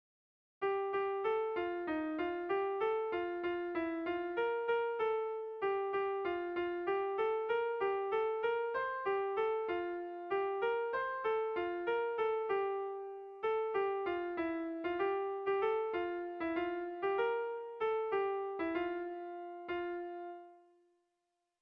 Dantzakoa
ABD